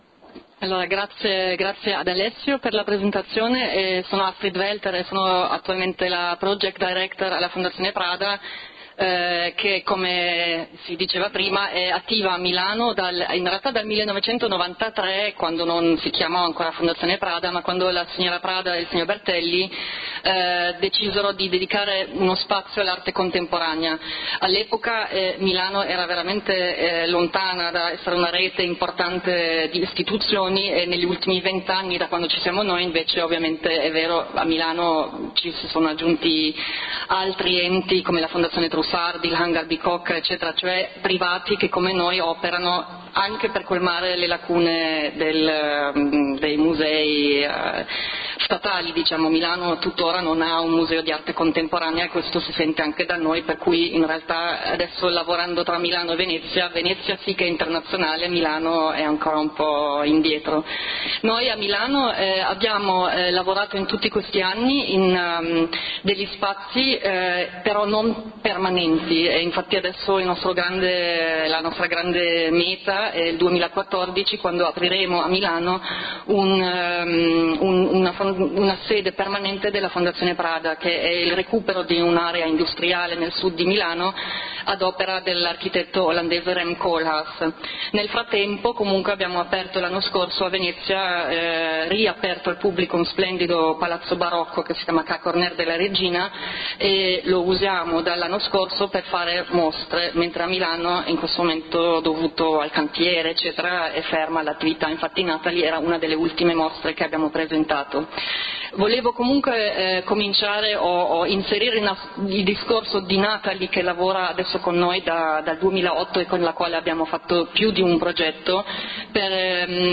LECTURE / On Nathalie Djurberg